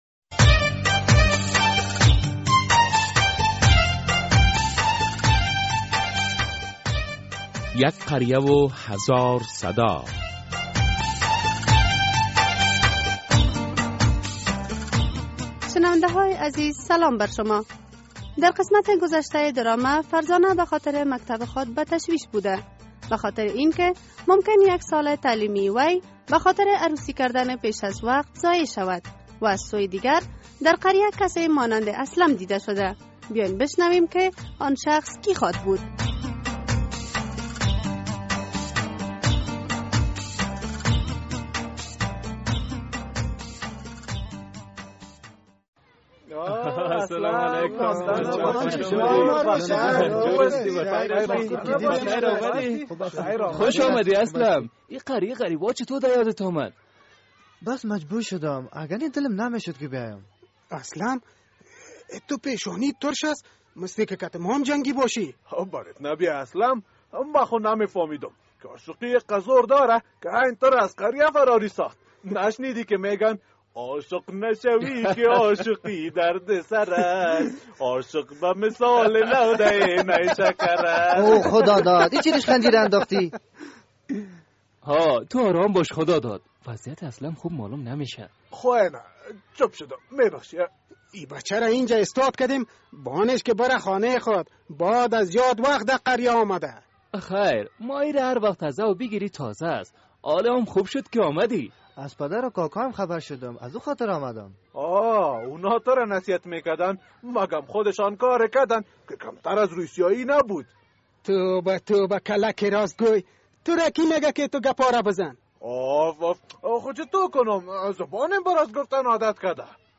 در این درامه که موضوعات مختلف مدنی، دینی، اخلاقی، اجتماعی و حقوقی بیان می گردد هر هفته به روز های دوشنبه ساعت ۳:۳۰ عصر از رادیو آزادی نشر می گردد.